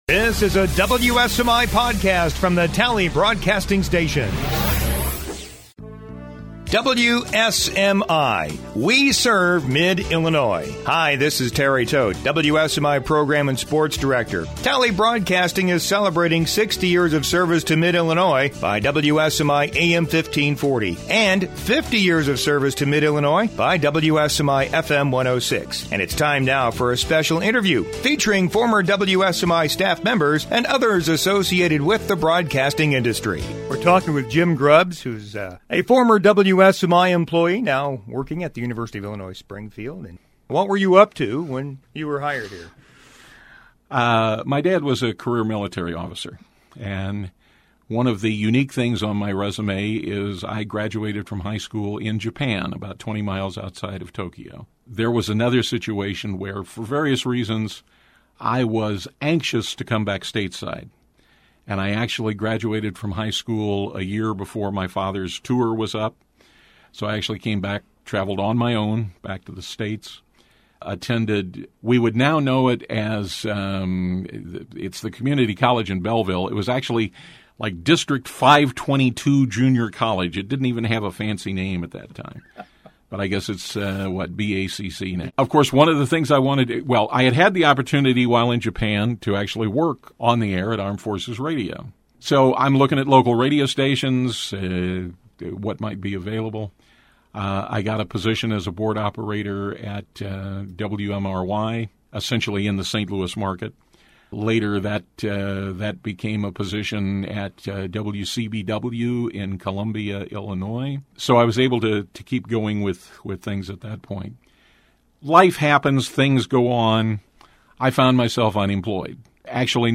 Podcasts - Interviews & Specials